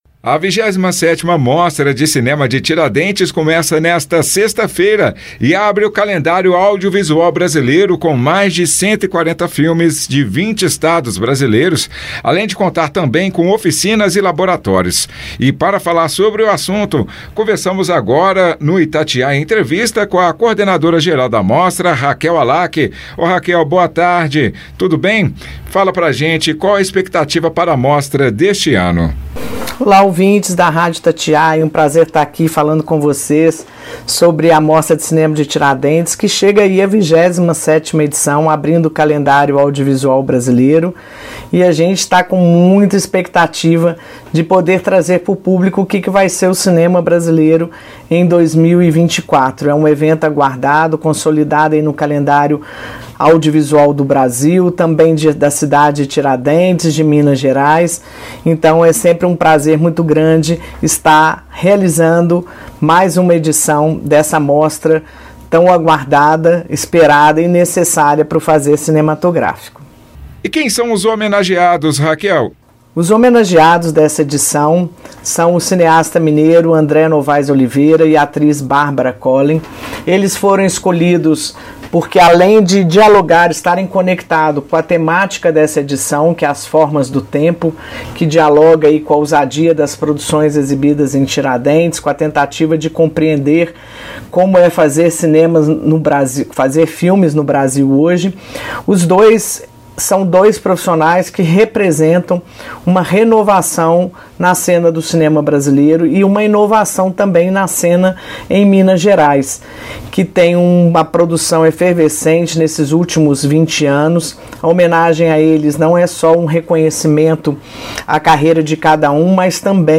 Itatiaia-Entrevista-Mostra-de-Cinema-de-Tiradentes.mp3